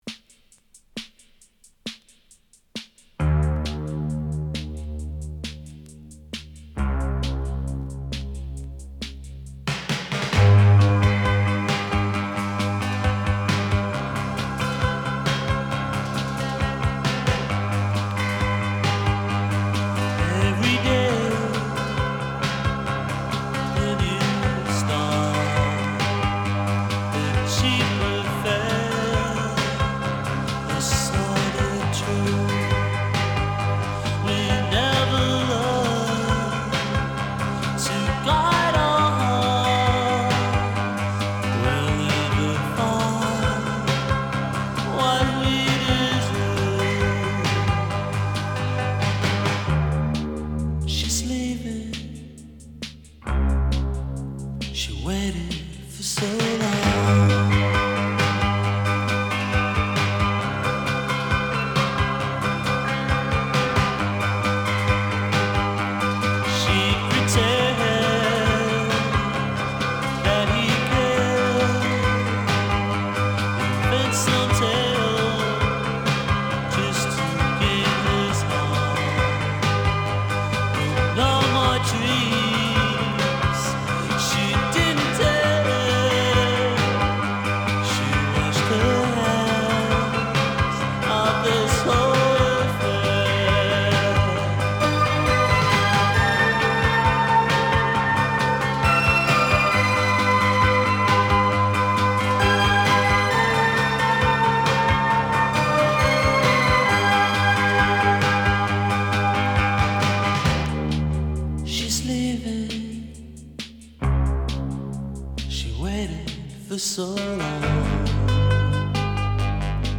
Genre: Synth Pop.